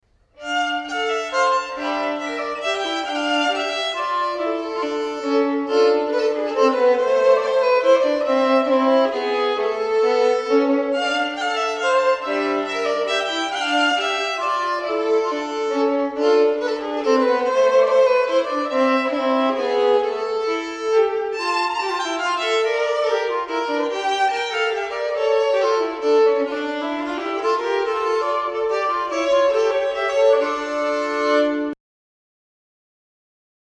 Two violins